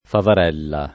vai all'elenco alfabetico delle voci ingrandisci il carattere 100% rimpicciolisci il carattere stampa invia tramite posta elettronica codividi su Facebook favarella [ favar $ lla ] o faverella [ faver $ lla ] s. f. — sim. il top.